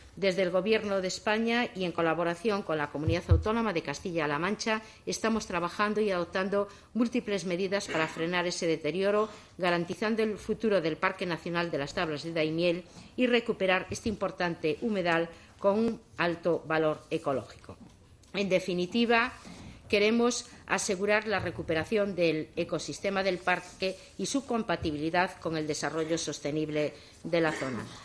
Cortes de audio de la rueda de prensa
Espinosa-Tablas.mp3